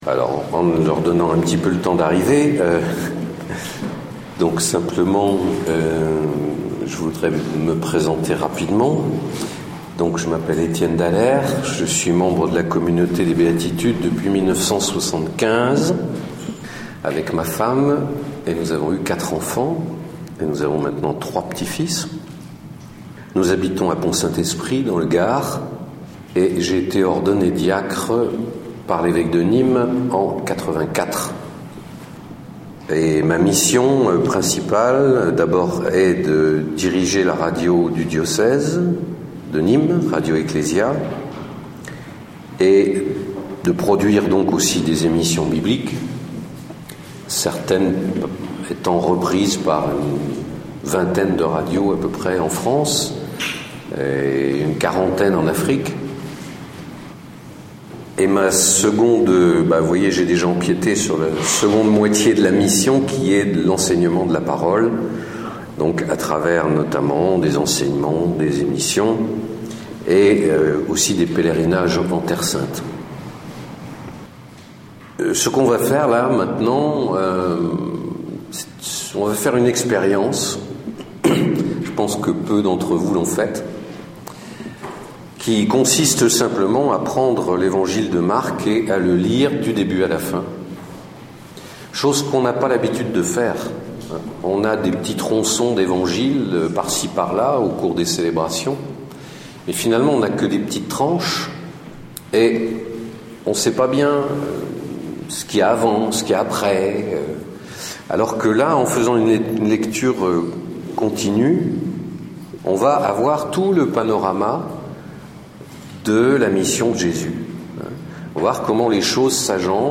Enseignement
Lecture intégrale de l'Evangile selon St Marc - 1&2
Un Evangile, un acteur, une voix, une expérience brûlante à vivre.
Enregistré en 2013 (Session Béatitudes Lourdes)
Format :MP3 64Kbps Mono